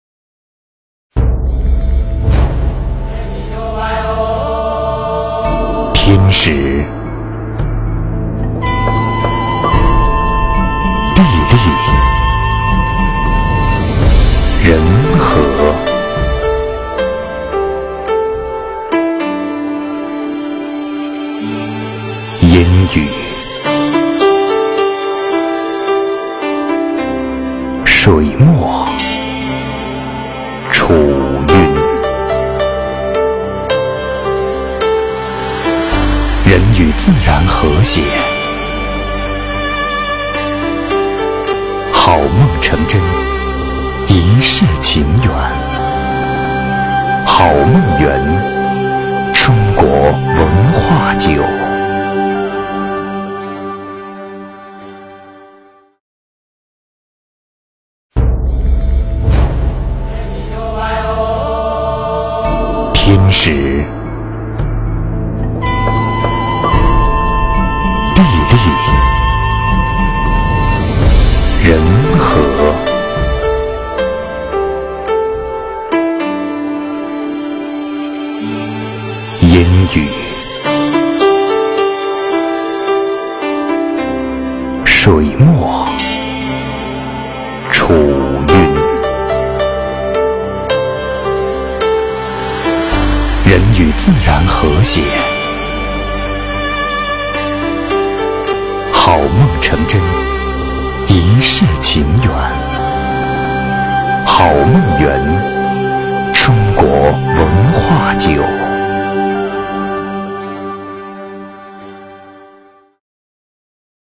国语中年激情激昂 、大气浑厚磁性 、积极向上 、男广告 、300元/条男S337 国语 男声 广告-赖茅父亲节-随性、自然 激情激昂|大气浑厚磁性|积极向上 - 样音试听_配音价格_找配音 - voice666配音网